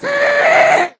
scream1.ogg